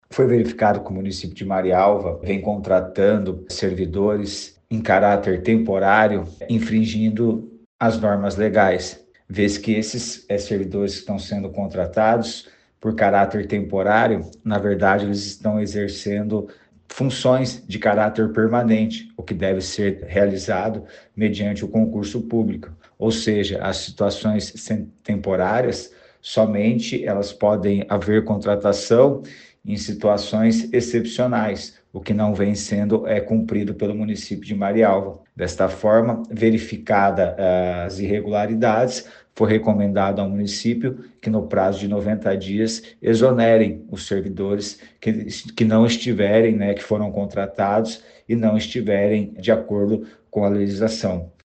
Ouça o que diz o promotor de Justiça, Sidiklei Rosolen de Oliveira.